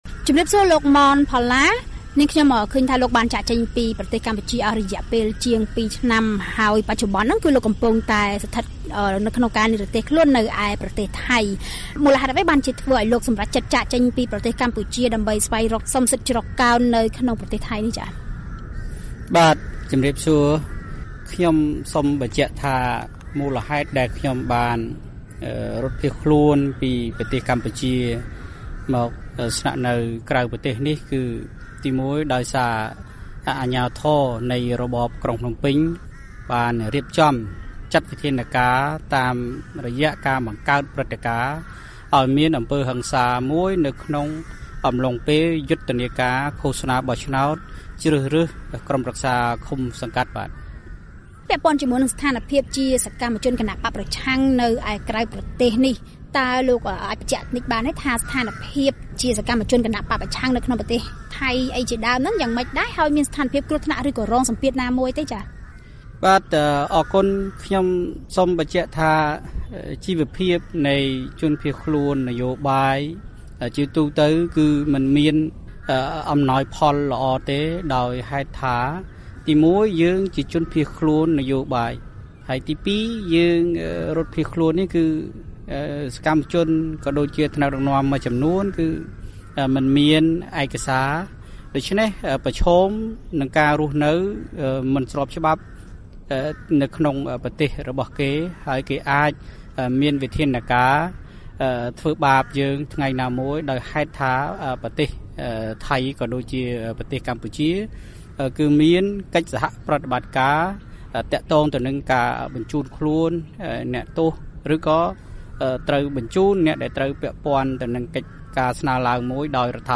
បទសម្ភាសន៍ VOA៖ សកម្មជនបក្សប្រឆាំងដែលកំពុងនិរទេសខ្លួននៅថៃ ត្រៀមអមដំណើរលោក សម រង្ស៊ី ចូលកម្ពុជាវិញ